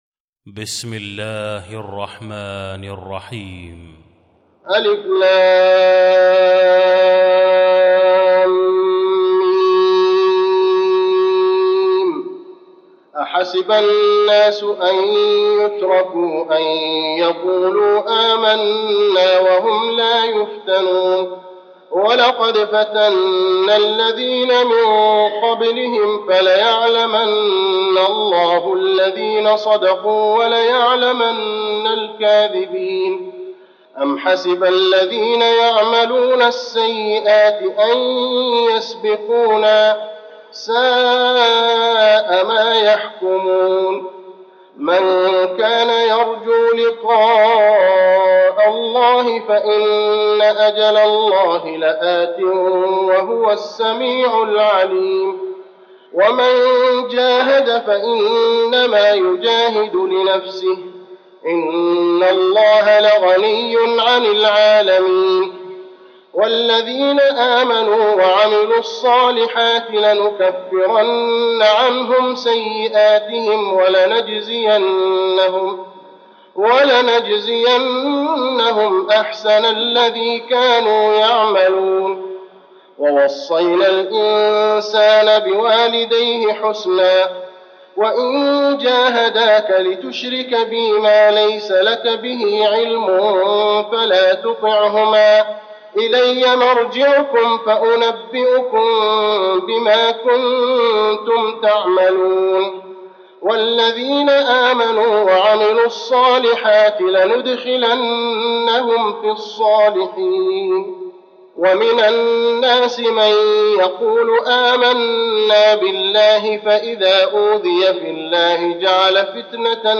المكان: المسجد النبوي العنكبوت